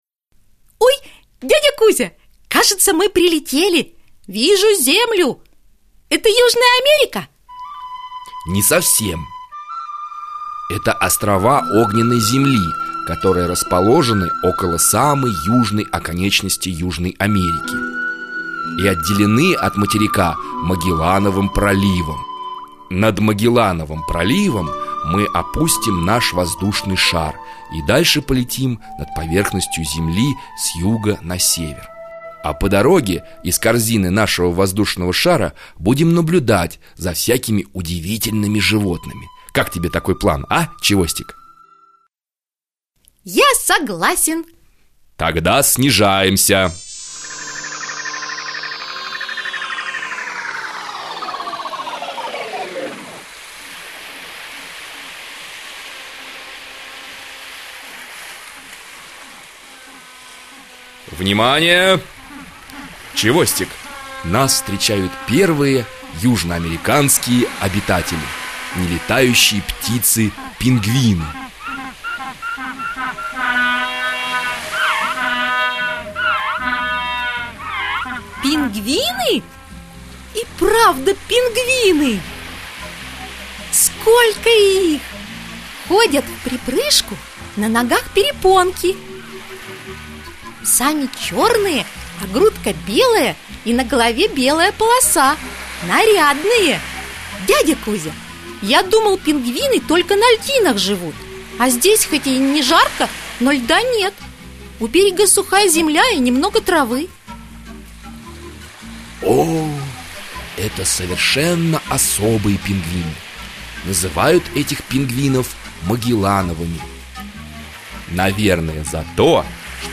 Аудиокнига Животные Южной Америки | Библиотека аудиокниг